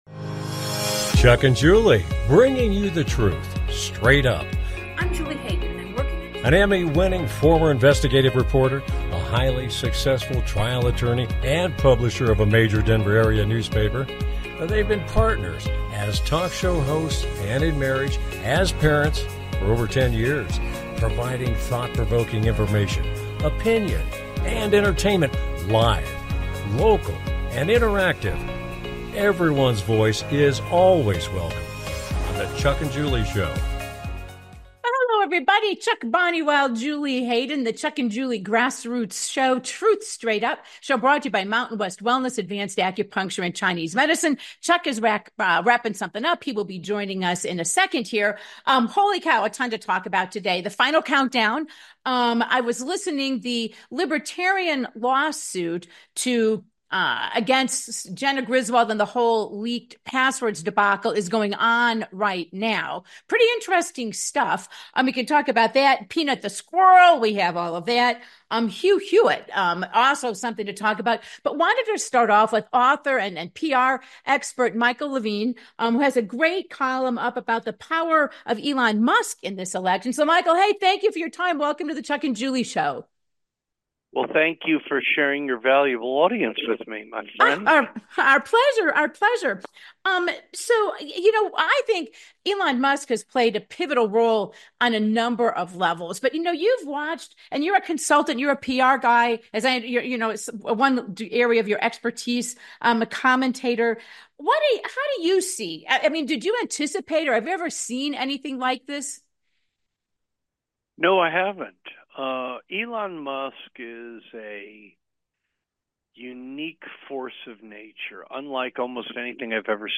Talk Show Episode, Audio Podcast
Their program is a live Internet call-in talk show providing thought provoking information, conversation and entertainment.